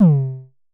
Festival Kicks 25 - C3.wav